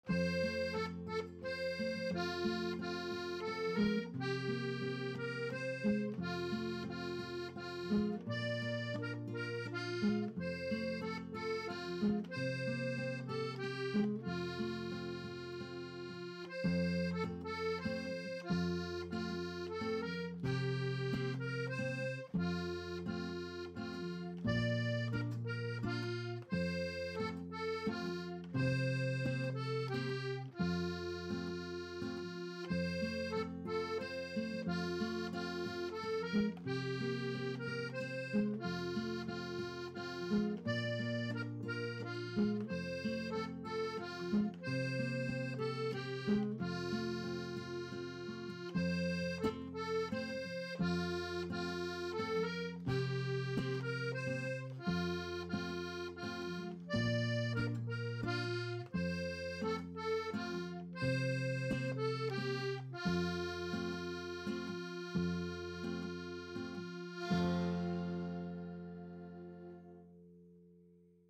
Musique : accordéon - 887 Ko 1 mn 11 :